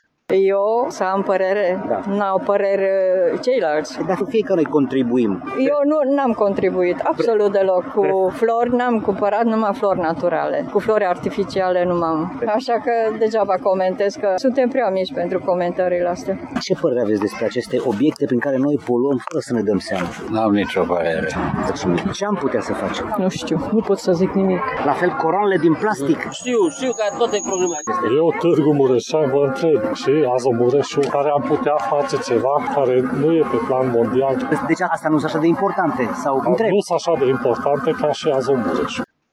În general, cetățenii chestionați nu au dorit să comenteze, replicând că ei nu poluează și că sunt lucruri mai importante decât coroanele de plastic: